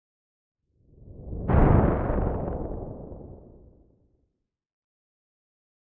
描述：雷鸣般的噪音由白噪声雕刻而成。 有一些咔嚓声和爆裂声，但不是太坏。
标签： 合成 合成的
声道立体声